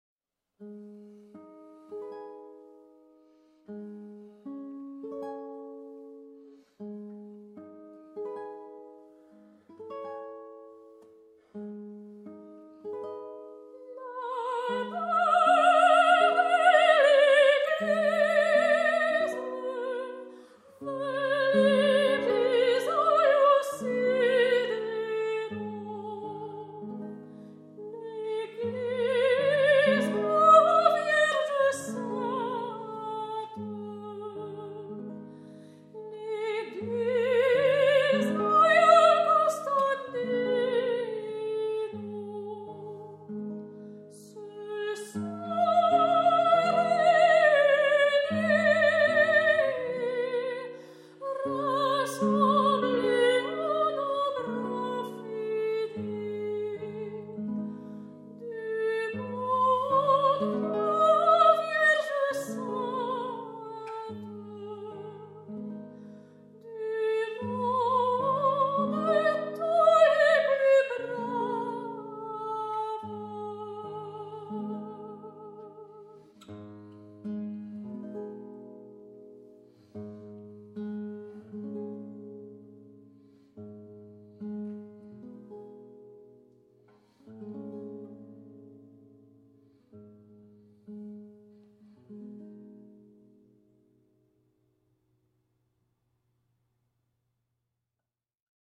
guitariste
soprano
Ils se consacrent à l’interprétations du répertoire classique et contemporain, ainsi qu’à la commande de nouvelles œuvres auprès de compositeurs et compositrices actuels, mettant en avant la complémentarité de la guitare et de la voix.